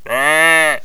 sheep1.wav